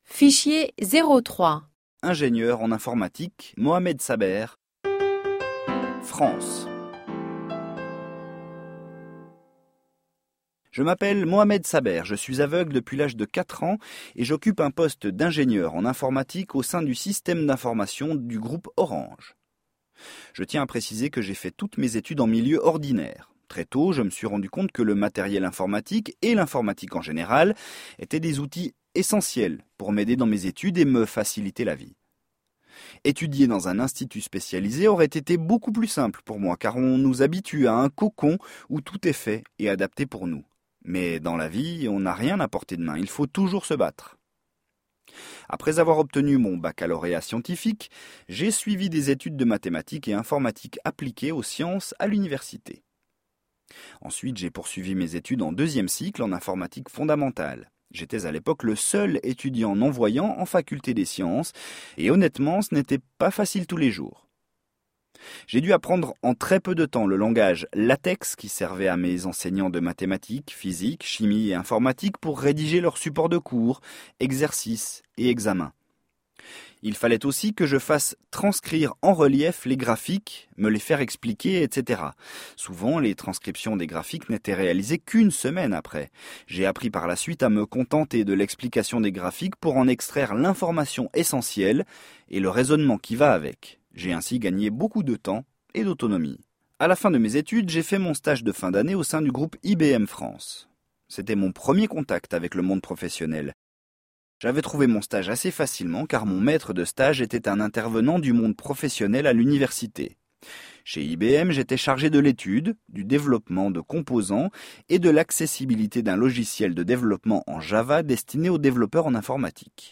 Please enjoy the below audio interview with him.